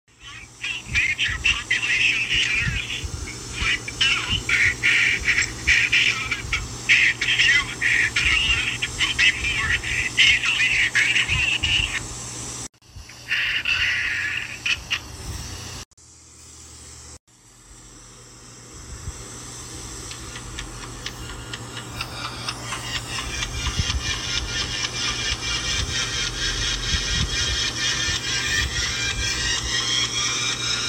Creepy Area 51 call PART sound effects free download